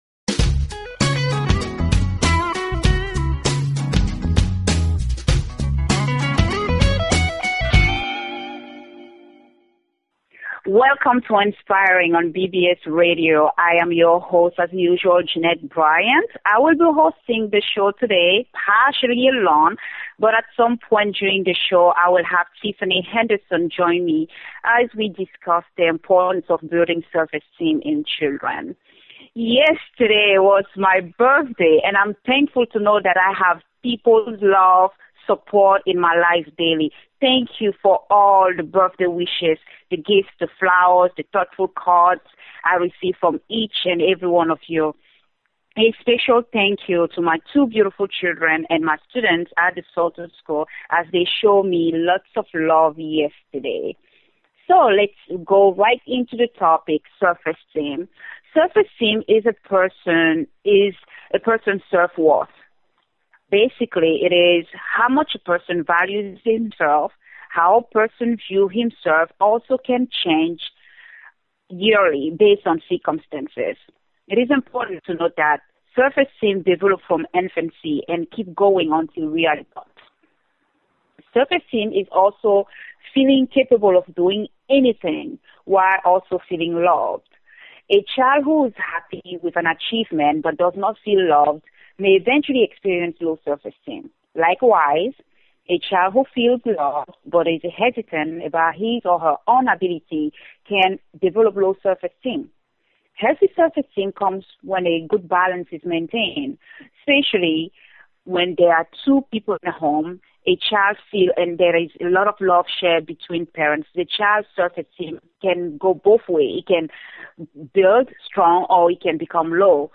Talk Show Episode, Audio Podcast, Inspiring and Courtesy of BBS Radio on , show guests , about , categorized as